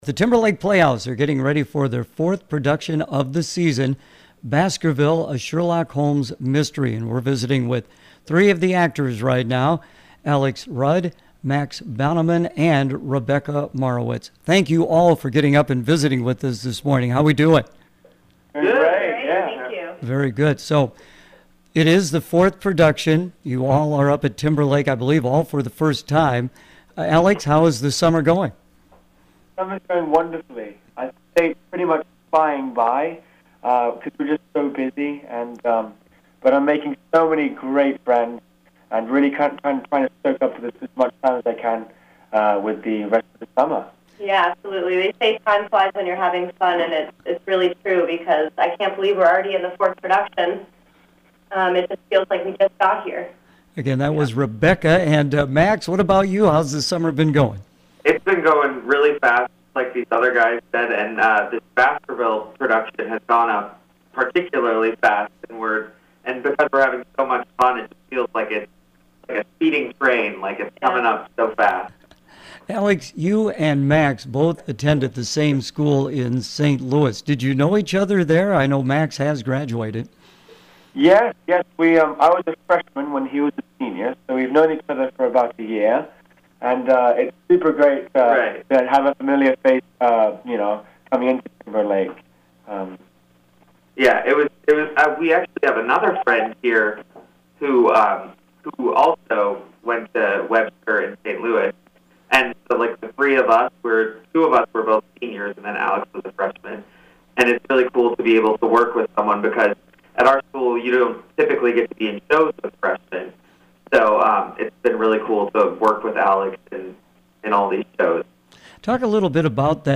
conversation
TLP-Baskerville-Intv-7-17-18.mp3